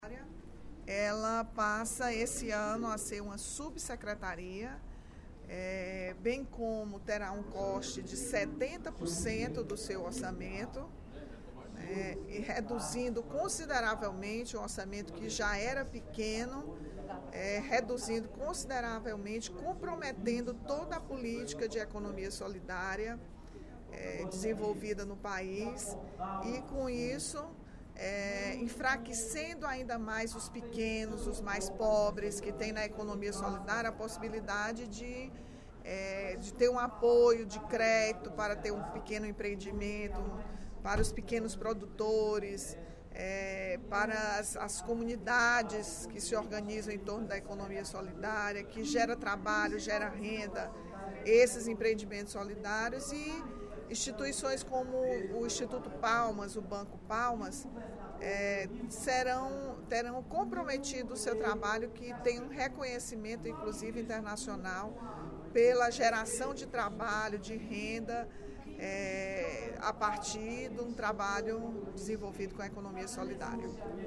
A deputada Rachel Marques (PT) lamentou, durante o primeiro expediente da sessão plenária desta sexta-feira (05/05), o corte de 70% na verba e o rebaixamento da Secretaria Nacional de Economia Solidária, que agora é uma subsecretaria.